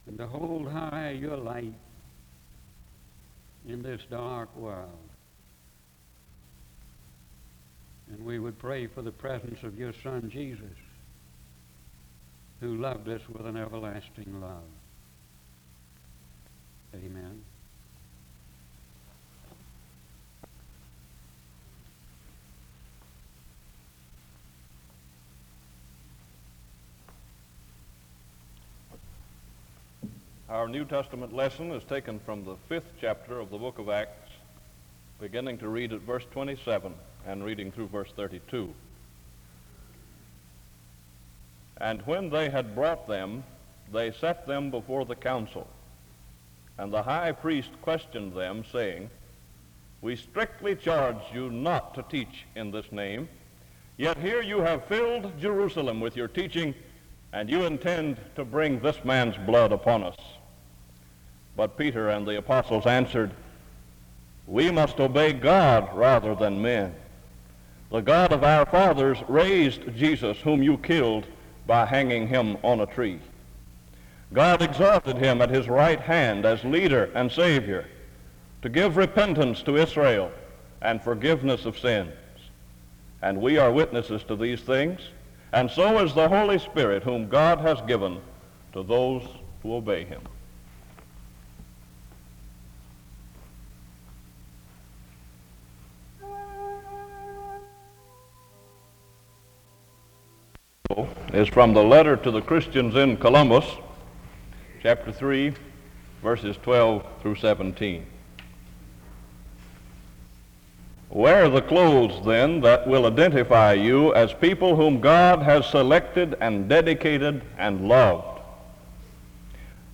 Accueil SEBTS Chapel - Clarence...